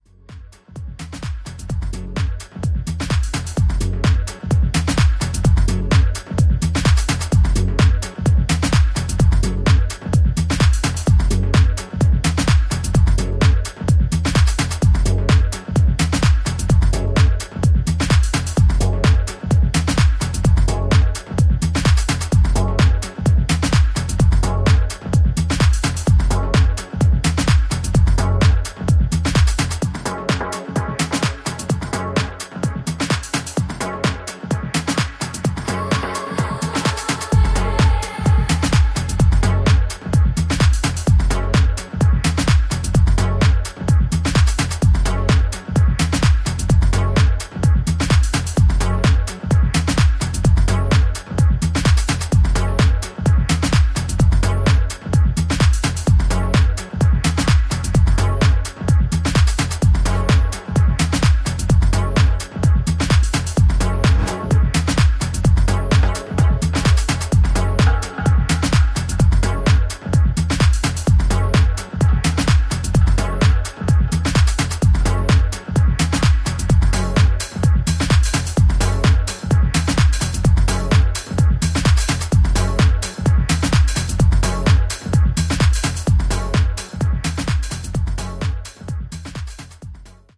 ジャンル(スタイル) TECH HOUSE